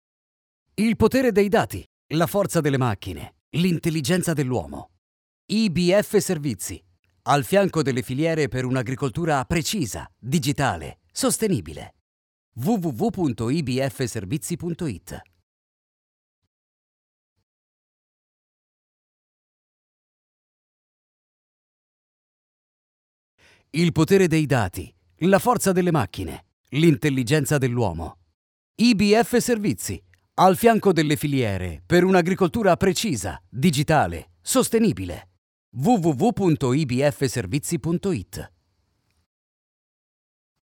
Commerciale, Profonde, Naturelle, Distinctive, Corporative
Vidéo explicative